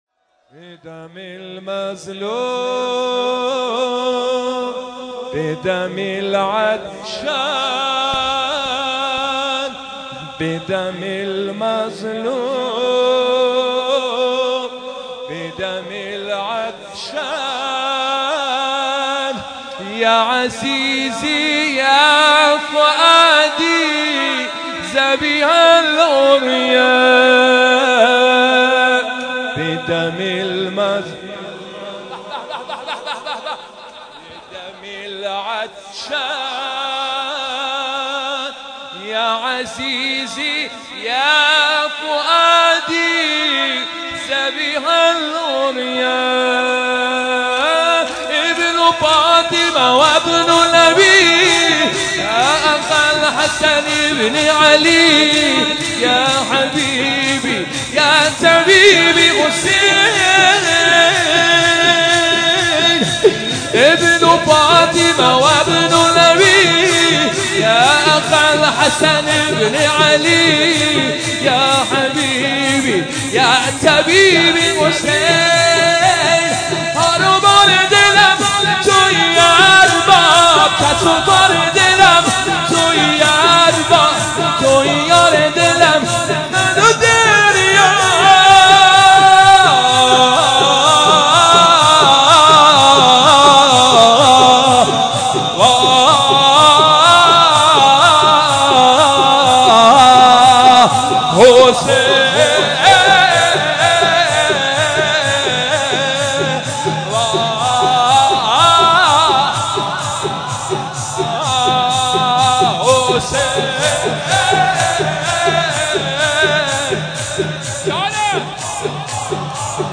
مداحی شب اول محرم 1399 با نوای حاج حسین سیب سرخی